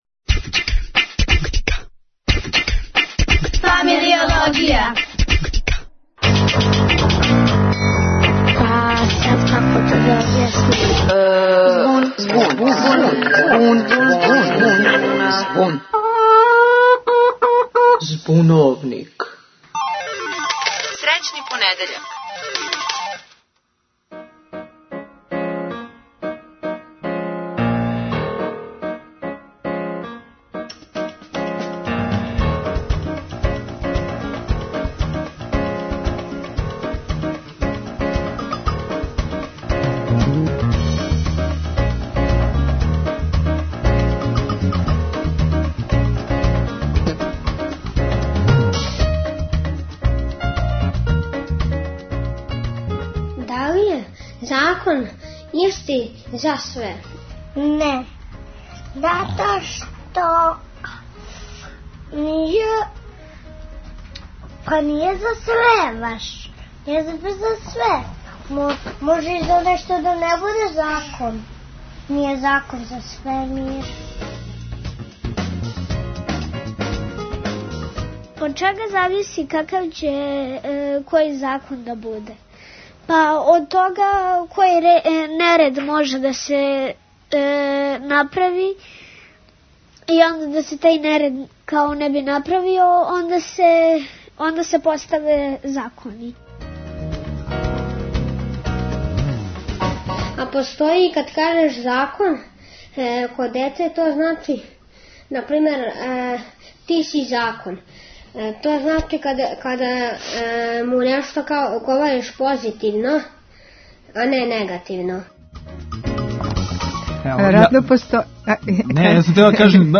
Закон и норме, данас о томе. Говоре деца и млади.